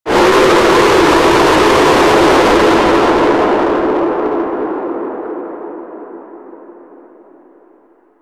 tsm_scream.ogg